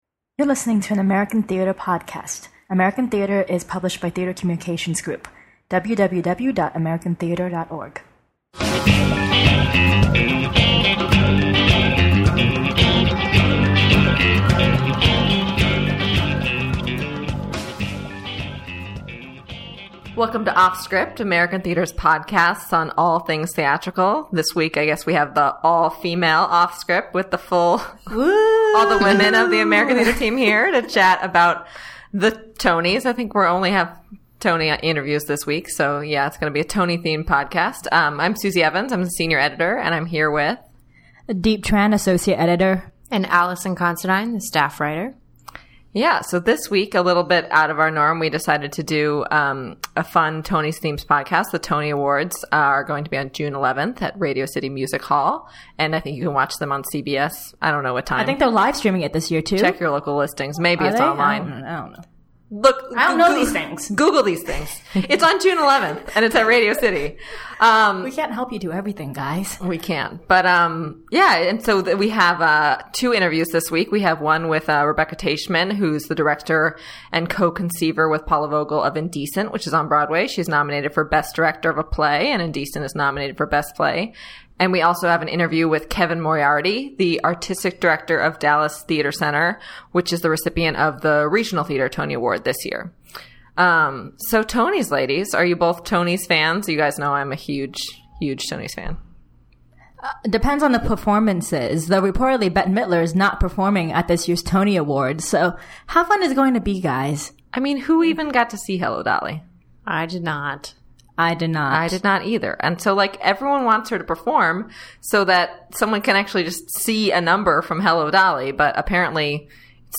Every other week, the editors of American Theatre curate a free-ranging discussion about the lively arts in our Offscript podcast.
This week, it’s an all-female Tony Awards discussion!